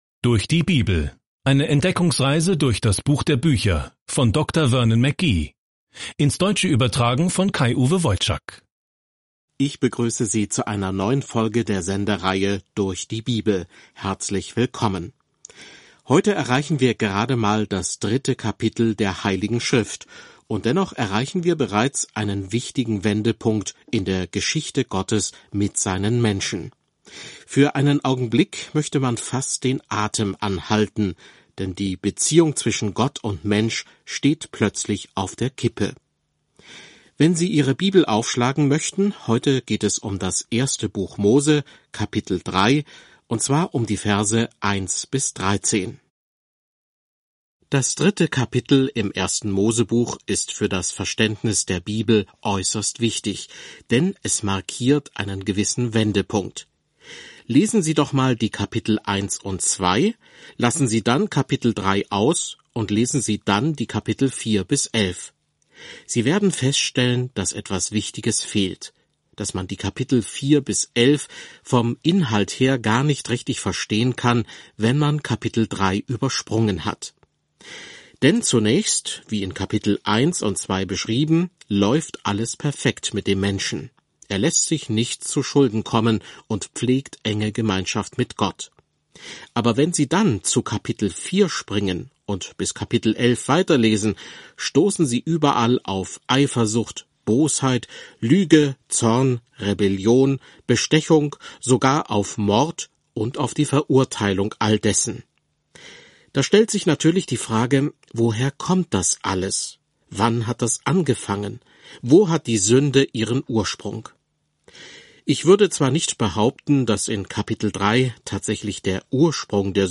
Mose 3:1-13 Tag 5 Diesen Leseplan beginnen Tag 7 Über diesen Leseplan Hier beginnt alles – das Universum, die Sonne und der Mond, Menschen, Beziehungen, Sünde – alles. Reisen Sie täglich durch die Genesis, während Sie sich die Audiostudie anhören und ausgewählte Verse aus Gottes Wort im Buch Genesis lesen.